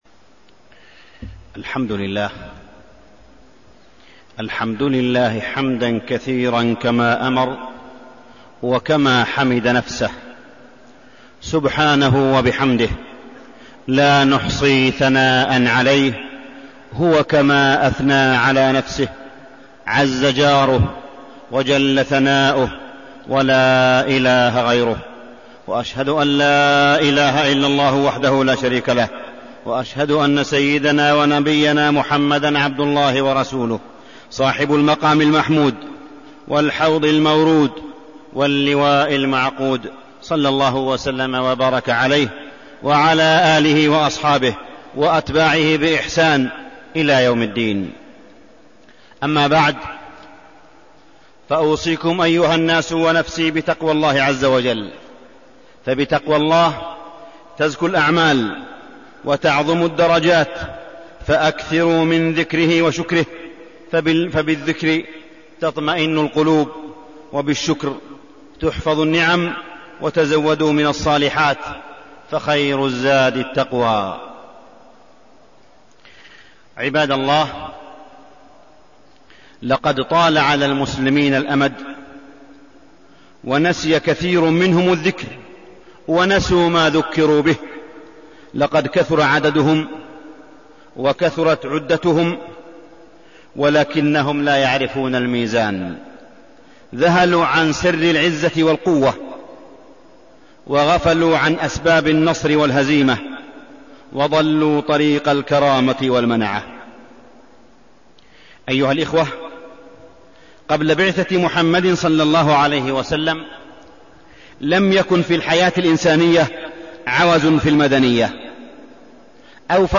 تاريخ النشر ١٤ رجب ١٤١٨ هـ المكان: المسجد الحرام الشيخ: معالي الشيخ أ.د. صالح بن عبدالله بن حميد معالي الشيخ أ.د. صالح بن عبدالله بن حميد غزوة حنين دروس وعبر